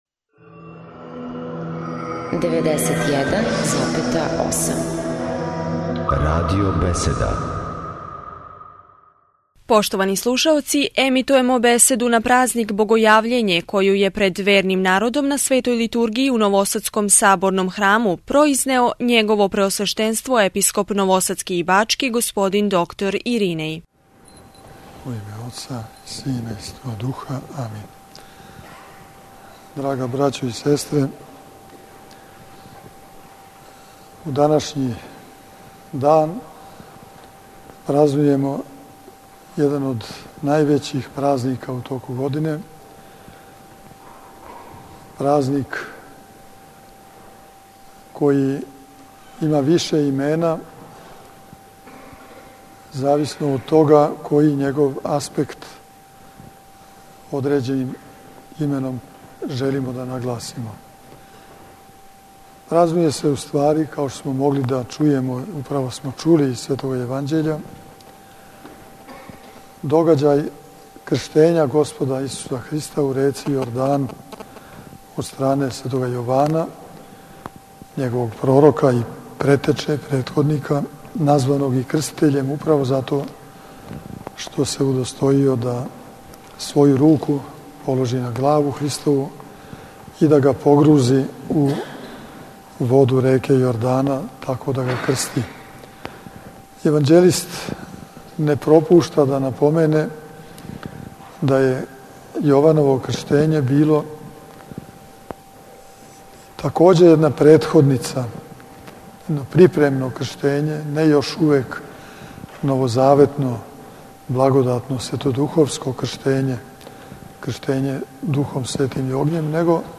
Прослава празника Богојављења у новосадској Саборној цркви
Великим евхаристијским сабрањем вернога народа, којим је у новосадској Светогеоргијевској цркви началствовао Његово Преосвештенство Епископ бачки Господин др Иринеј, прослављен је у четвртак, 6./19. јануара 2012. године, празник Богојављења.
Пред Часним Крстом, у порти Саборног храма, Епископ Иринеј служио је и чин Великог водоосвећења.